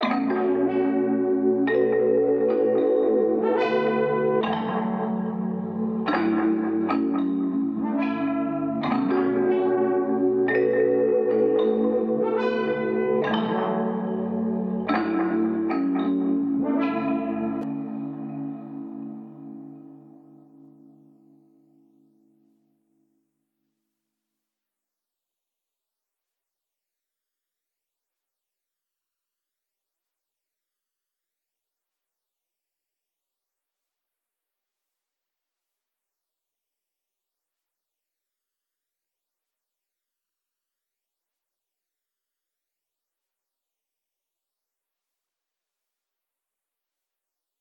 36_(Master) Cudi_109Bpm.wav